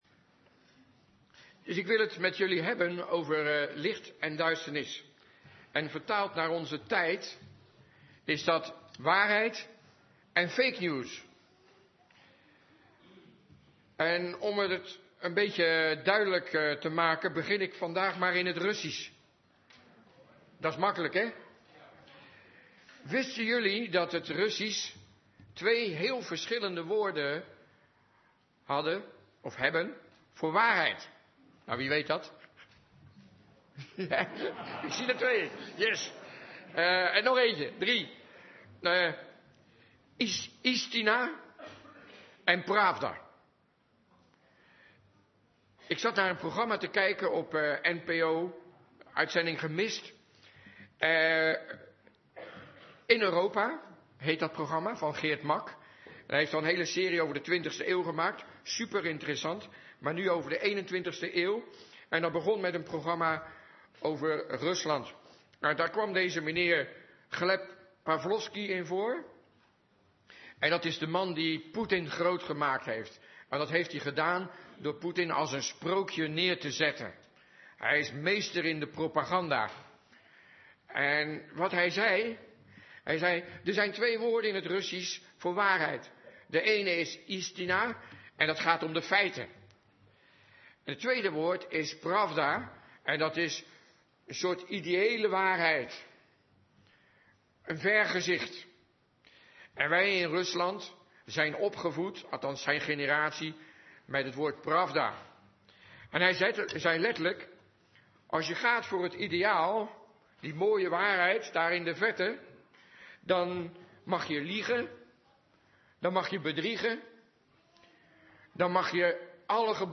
Toespraak van 1 maart 2020: Samen wandelen in het licht - De Bron Eindhoven